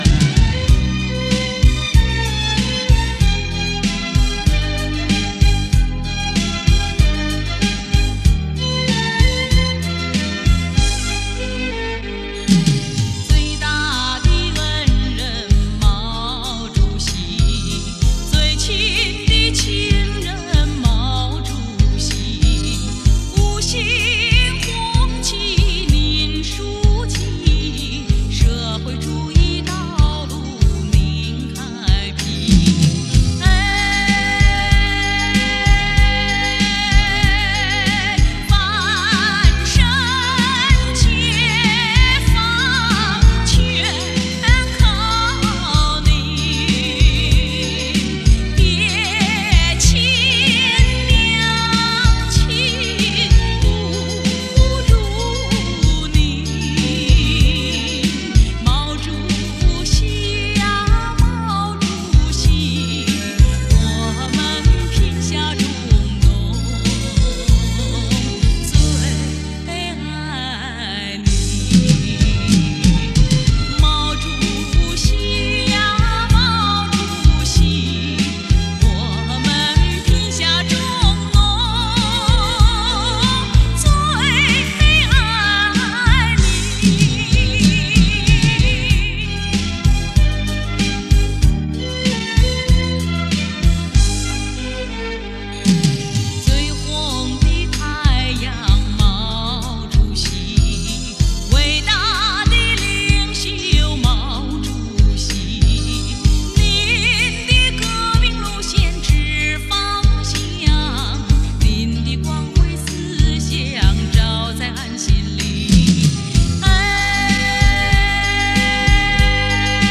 录音肯定没CD音质好，只是觉得这张专辑与其他歌颂毛主席的专